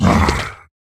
Minecraft Version Minecraft Version snapshot Latest Release | Latest Snapshot snapshot / assets / minecraft / sounds / mob / ravager / bite3.ogg Compare With Compare With Latest Release | Latest Snapshot
bite3.ogg